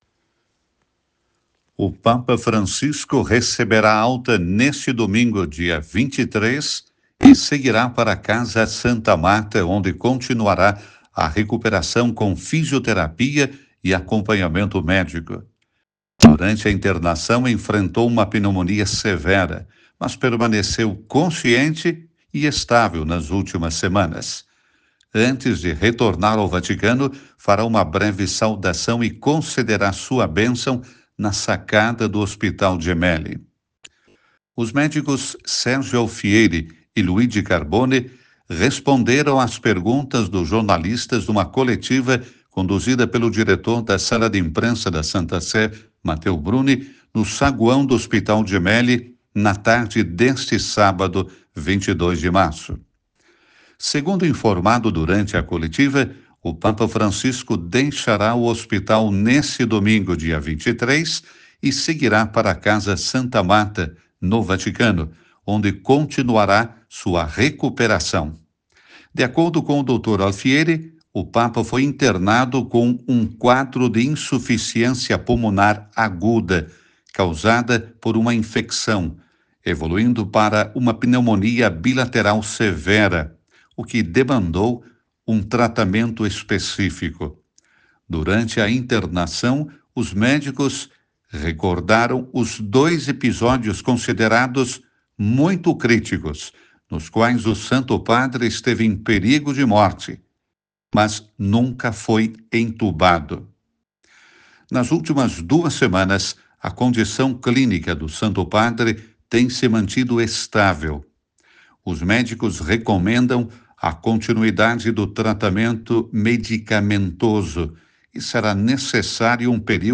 OUÇA O BOLETIM DA RÁDIO VATICANO